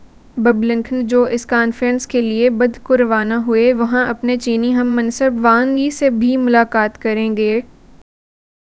Spoofed_TTS/Speaker_05/249.wav · CSALT/deepfake_detection_dataset_urdu at main